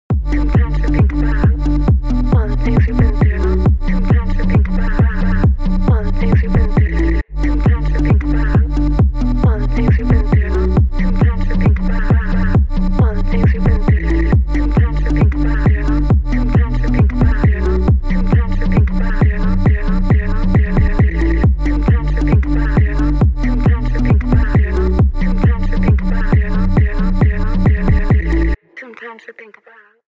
With Instant Sidechain